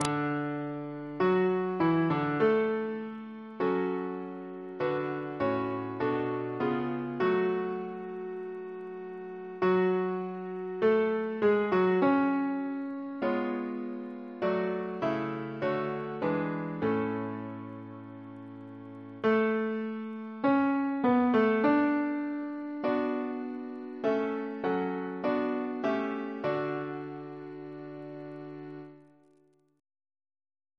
Triple chant in D Composer: C. Hylton Stewart (1884-1932), Organist of Rochester and Chester Cathedrals, and St. George's, Windsor Reference psalters: ACP: 170